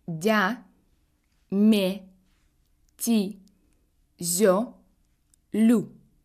2.Si la consonne est suivie d'une voyelle de deuxième série [4]: (я, е, и, ё, ю), on dit qu'elle est molle ou mouillée.